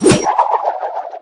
grenade_launch_01.ogg